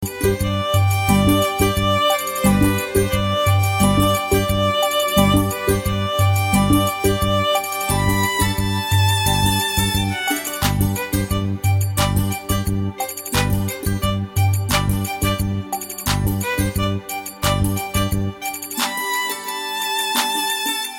نغمة ميكس سريع جدا
Bollywood